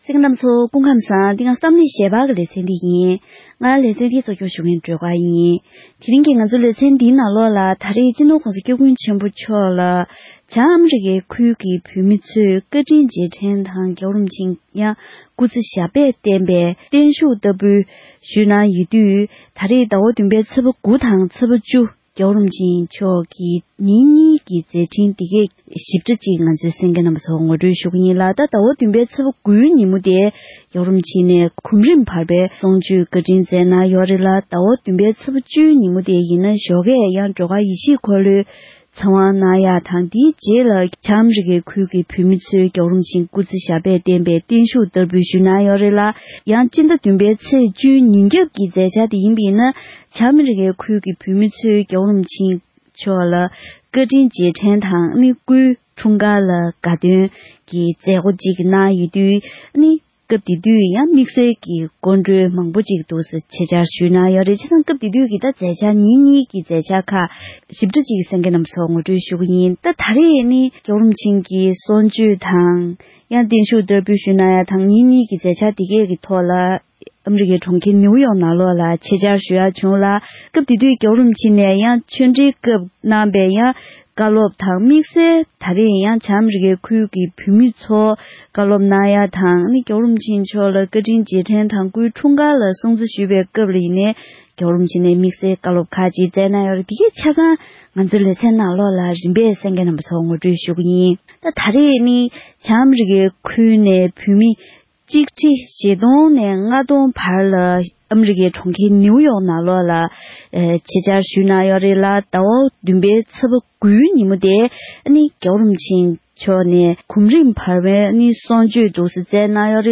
༄༅། །ཐེངས་འདིའི་གཏམ་གླེང་ཞལ་པར་ལེ་ཚན་ནང་། སྤྱི་ནོར་༸གོང་ས་༸སྐྱབས་མགོན་ཆེན་པོ་མཆོག་ནས་ཨ་རིའི་གྲོང་ཁྱེར་ནིའུ་ཡོག་ཏུ་བསྒོམ་རིམ་བར་པའི་གསུང་ཆོས་དང་བོད་མི་ཁྲི་བརྒལ་བར་བཀའ་སློབ་གནང་བའི་སྐོར་ངོ་ སྤྲོད་ཞུས་པ་ཞིག་གསན་རོགས་གནང་།